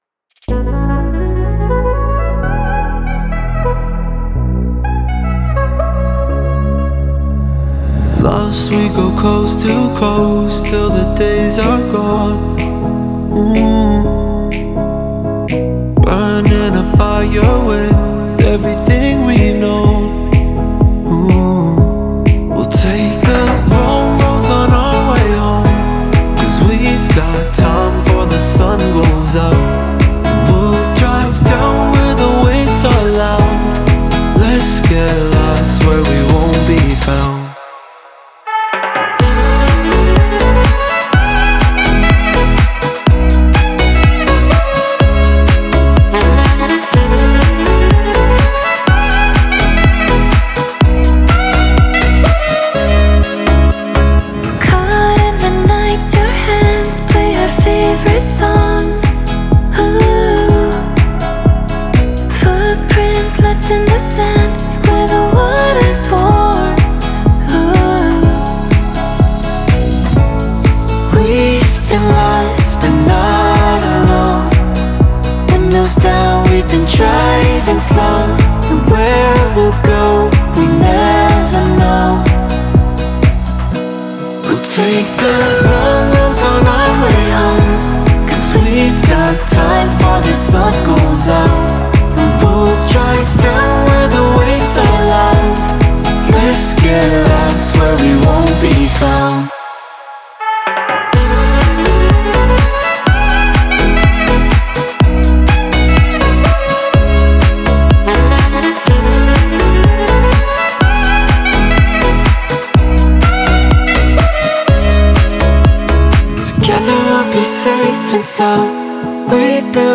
это трек в жанре EDM